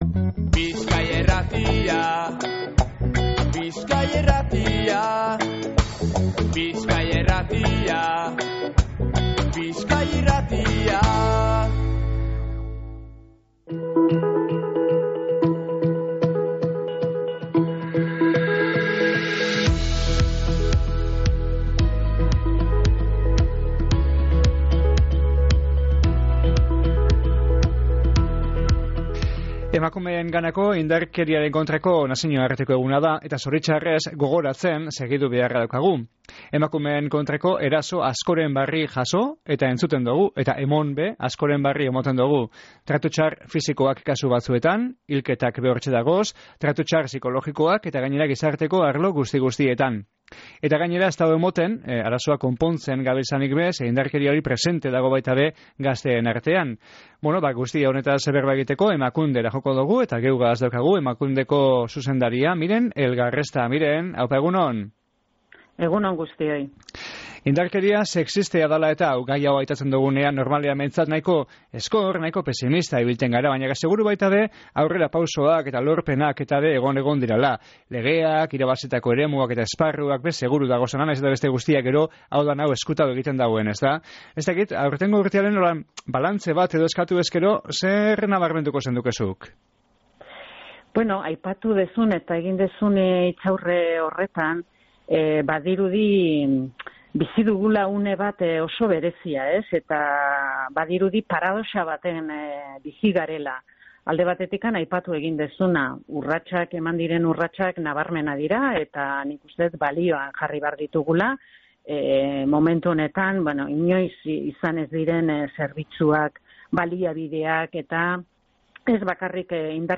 Emakundeko zuzendariagaz egin dogu berba Emakumeen Kontrako Indarkeriaren Aurkako Nazinoarteko Egunean